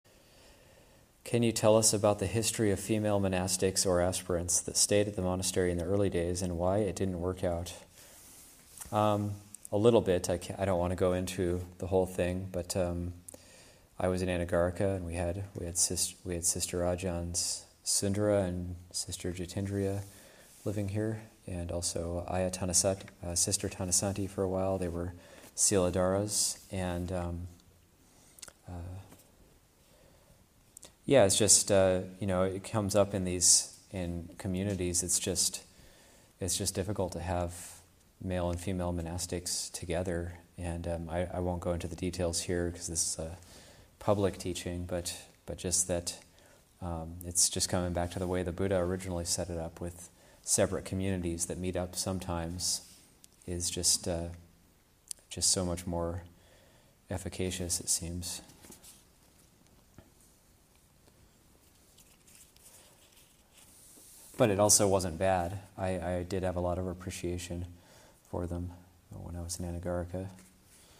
Abhayagiri 25th Anniversary Retreat, Session 16 – Jun. 13, 2021